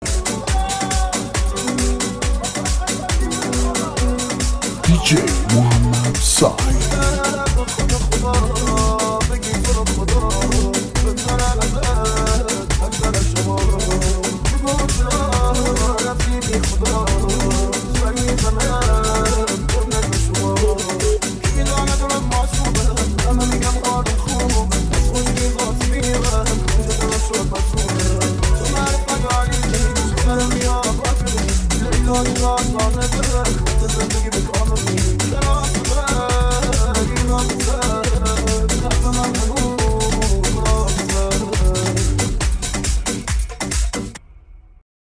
Iranian music